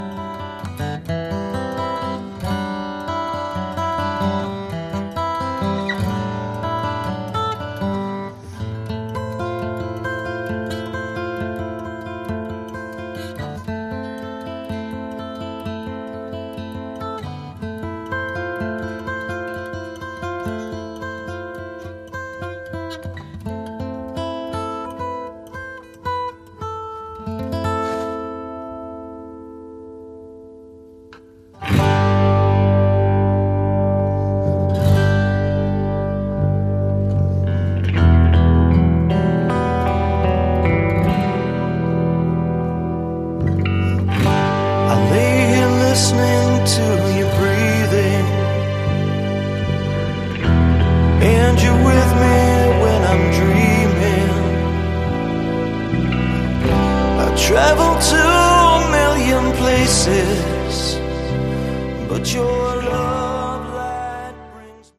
Category: AOR
drums, vocals
guitars, vocals
lead vocals
bass
guitar, vocals, keys